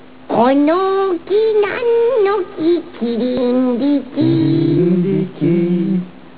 微妙に不協和音になっていたのではないかと。(笑)